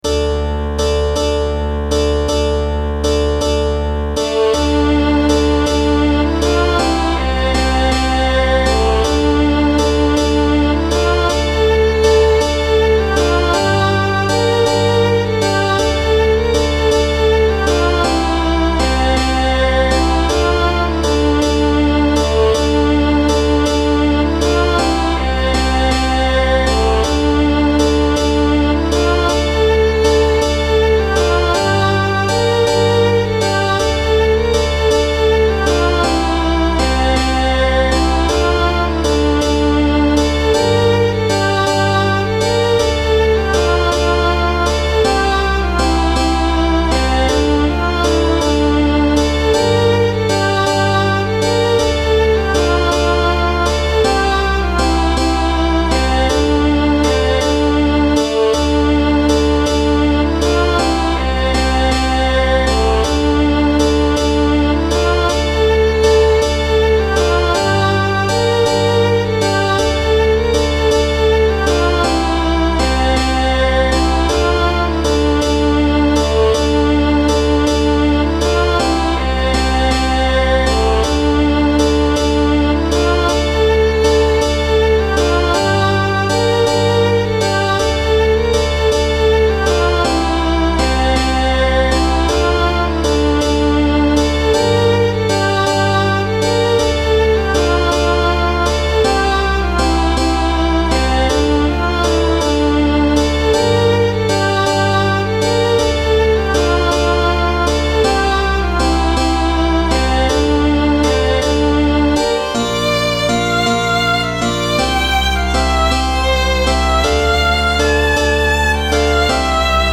The Buccaneer's Anchor (all instruments)